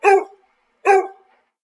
dog_barking_twice.ogg